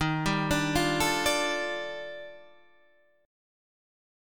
D#M#11 Chord